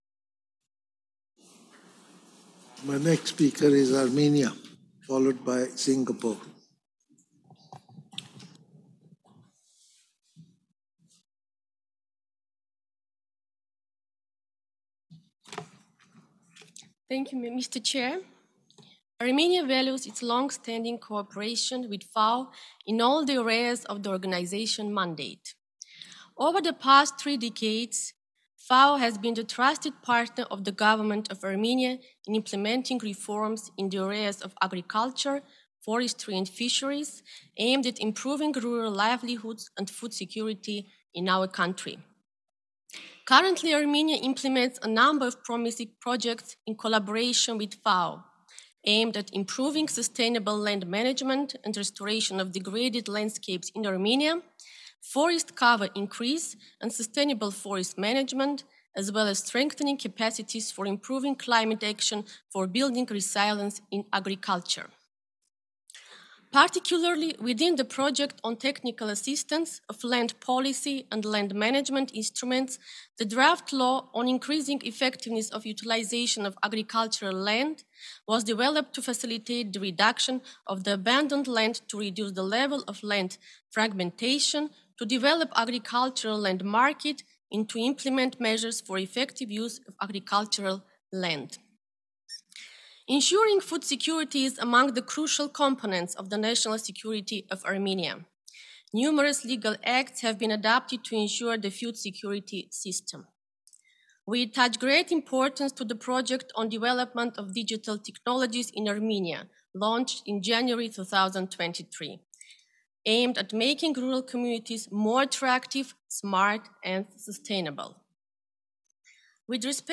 GENERAL DEBATE
Addresses and Statements
Ms Marieta Stepanyan Alternate Permanent Representative of Armenia
(Plenary – English)